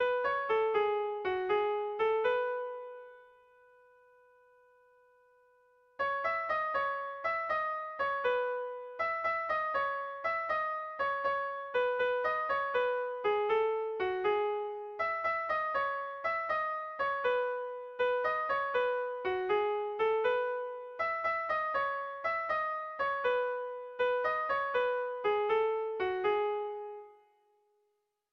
Irrizkoa
Zortzikoa, berdinaren moldekoa, 6 puntuz (hg) / Sei puntukoa, berdinaren moldekoa (ip)
ABBDBD